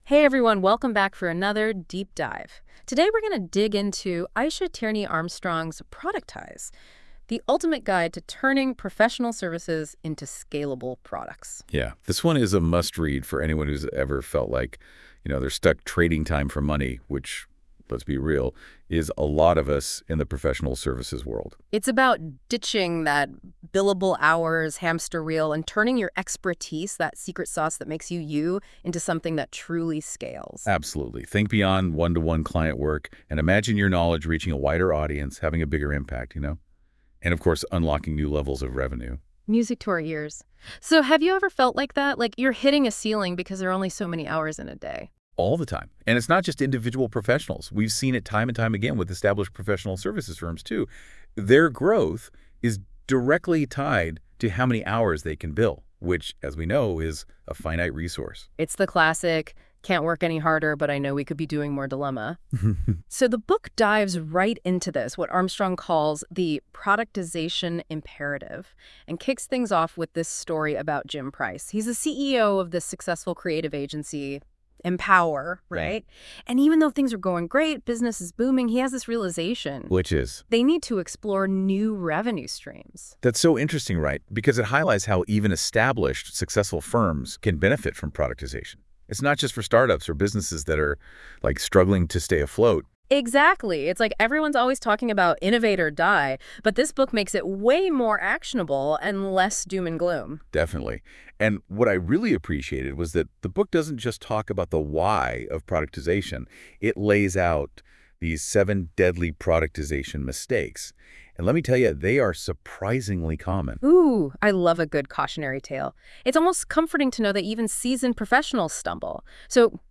Productize Podcast-Style AI-Overview (1).wav